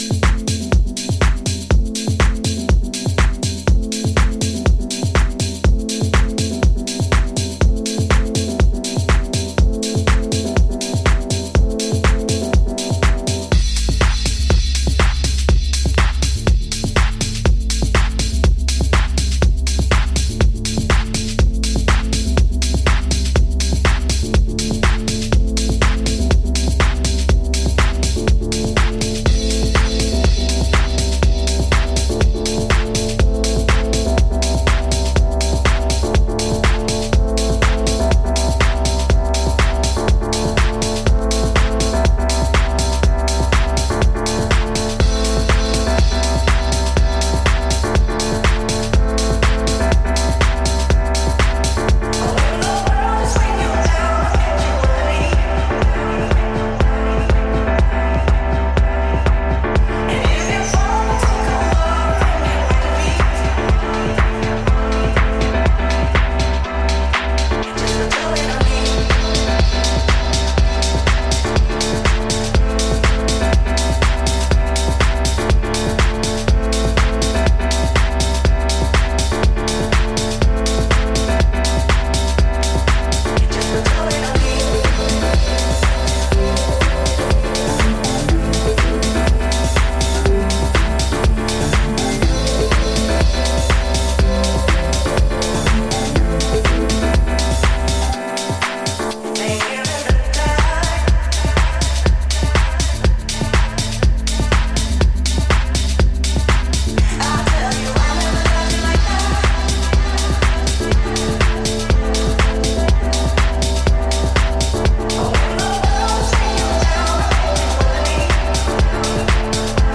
ジャンル(スタイル) HOUSE / DETROIT TECHNO